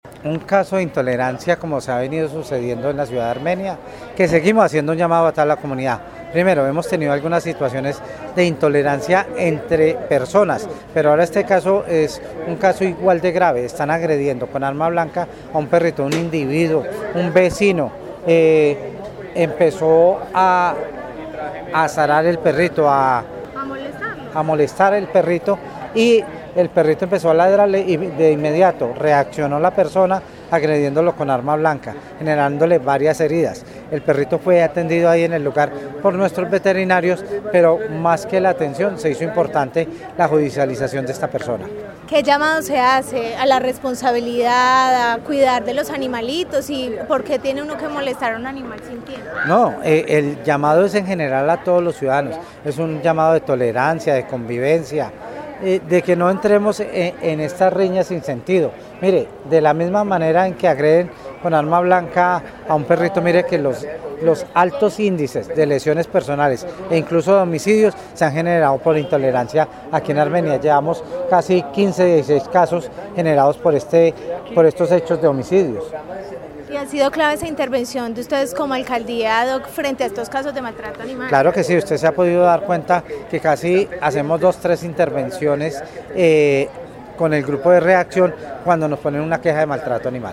Secretario de Gobierno de Armenia